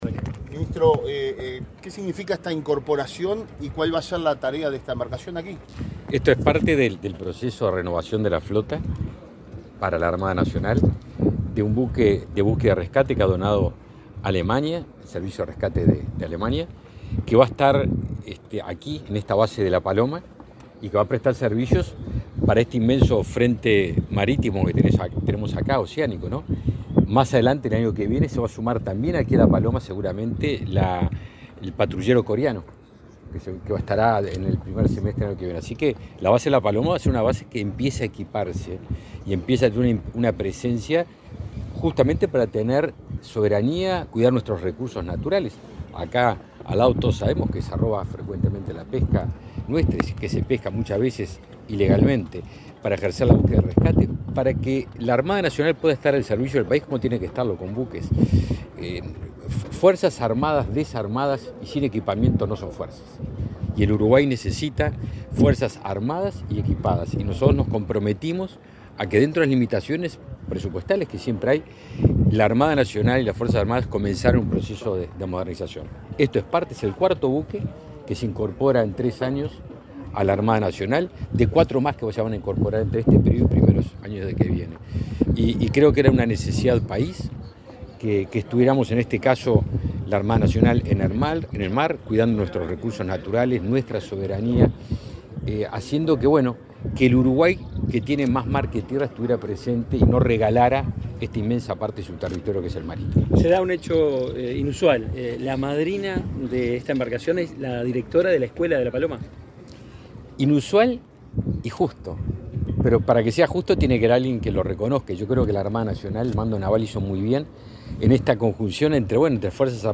Declaraciones del ministro de Defensa Nacional, Javier García
Declaraciones del ministro de Defensa Nacional, Javier García 18/11/2023 Compartir Facebook X Copiar enlace WhatsApp LinkedIn El ministro de Defensa Nacional, Javier García, dialogó con la prensa en Rocha, donde presenció el arribo al puerto de La Paloma de la embarcación de búsqueda y rescate de procedencia Alemana Alfried Krupp.